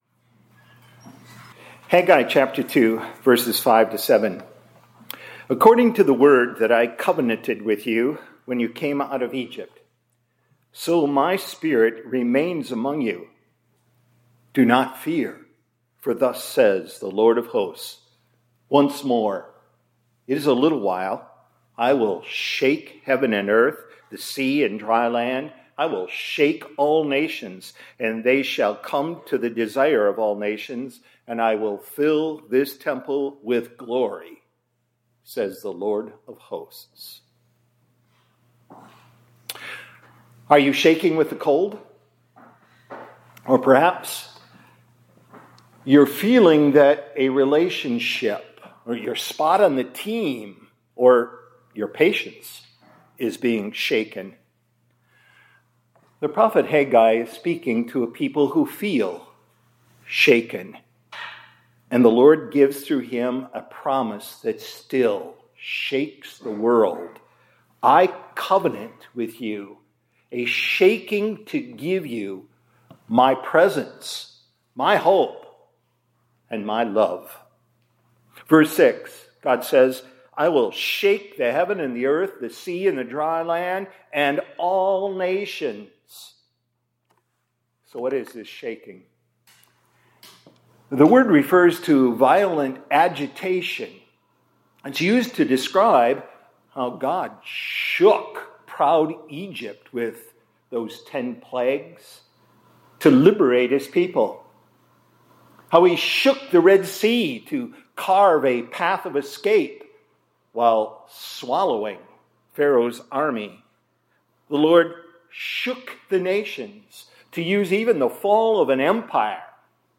2026-01-19 ILC Chapel — I covenant with you a shaking to give you My presence, hope, and love.